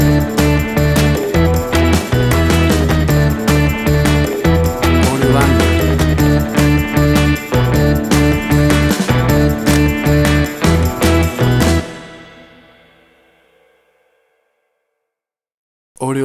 Tempo (BPM): 155